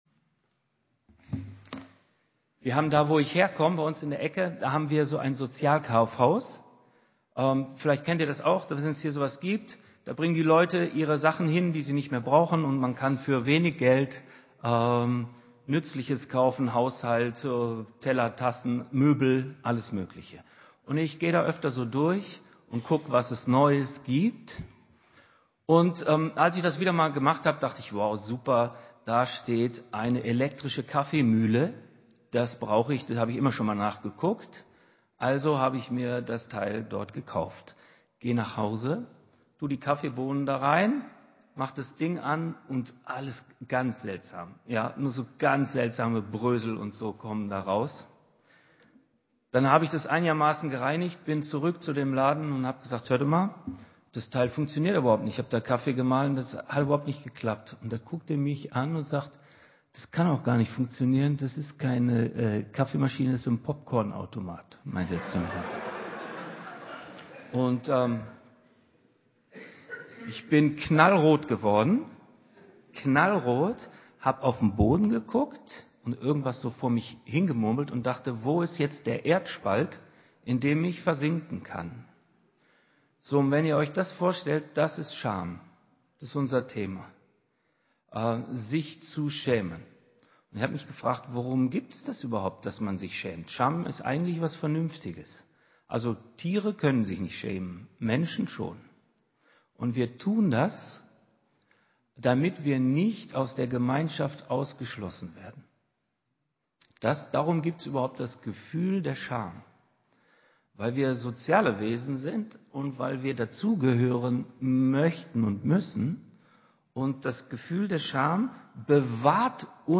Einen außergewöhnlich tiefgehenden und berührenden Suchthilfegruppen-Tag erlebten ca. 120 Menschen aus der Lausitz.